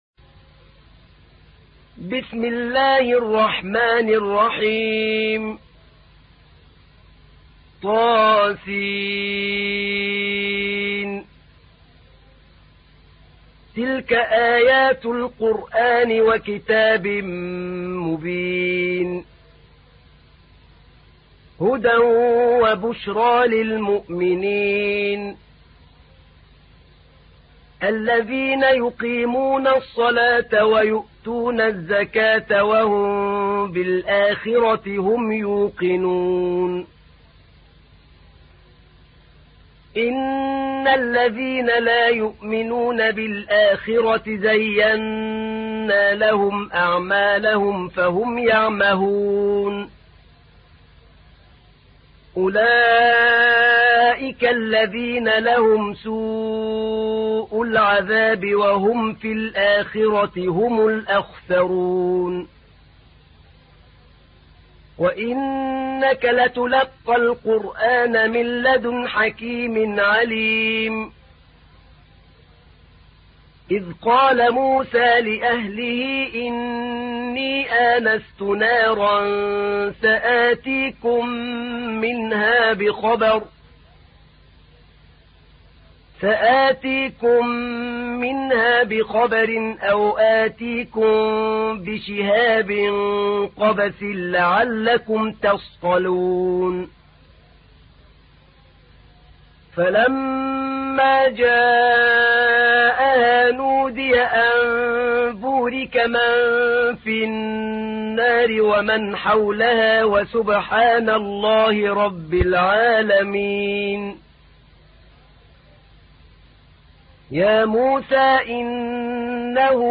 تحميل : 27. سورة النمل / القارئ أحمد نعينع / القرآن الكريم / موقع يا حسين